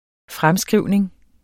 Udtale [ ˈfʁamˌsgʁiwˀneŋ ]